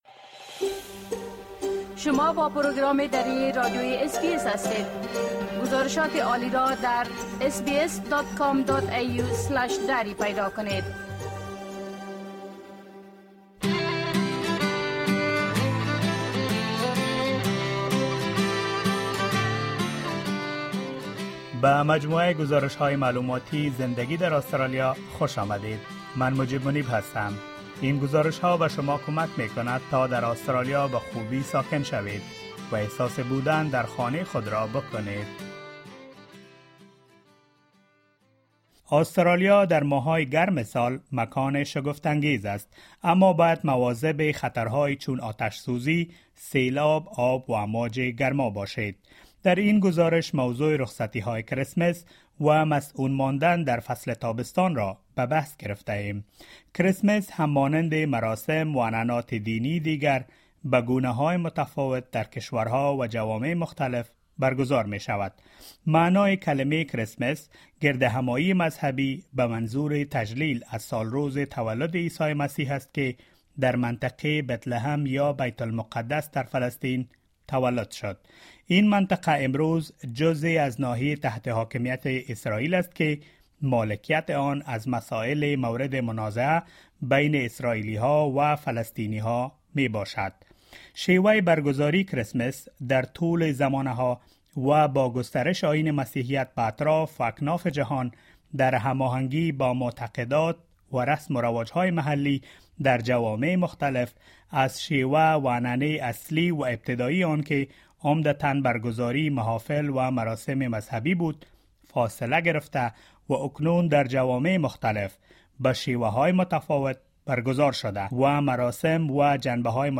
Australia is an amazing place during warmer months, but you should be aware of hazards such as bushfires, floods, water dangers and heatwaves. In this informative report, we have gathered information about holidays and summer safety in Australia.